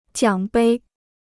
奖杯 (jiǎng bēi) Dictionnaire chinois gratuit